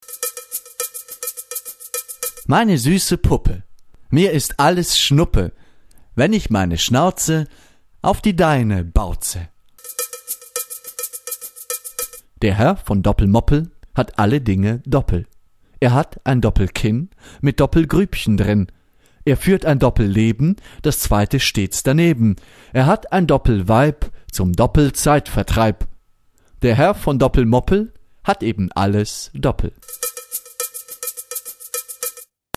Sprecherdemos